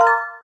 warning_notes.ogg